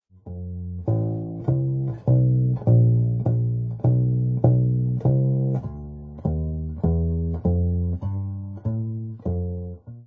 アンプはHartkeの「Kick Back」でエンハンサはオフ、イコライザはフラットに設定し、スピーカからの音を1mほどはなれた所に置いたレコーダで生録。
前も駒よりも落ち着いた音なったかなぁ。